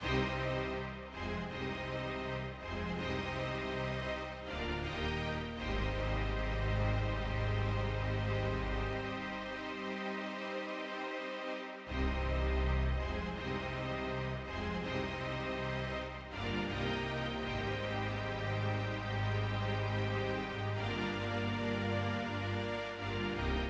11 strings 1 A.wav